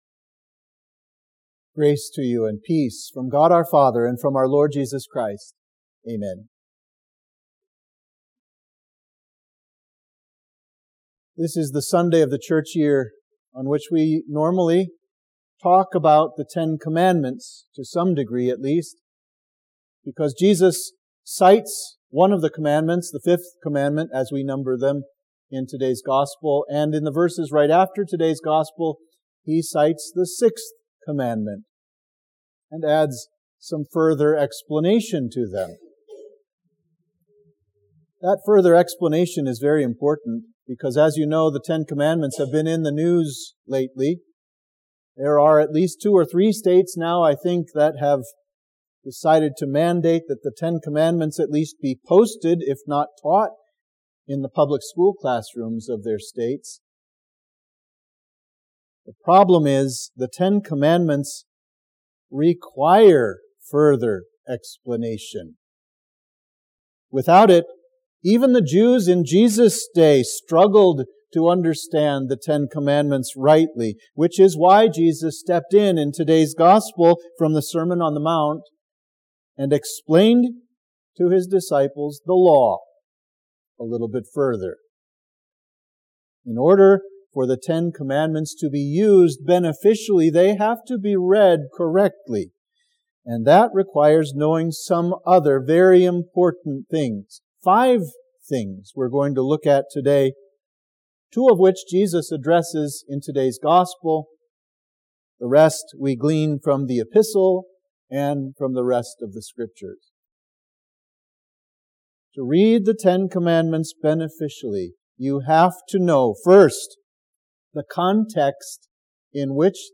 Sermon for Trinity 6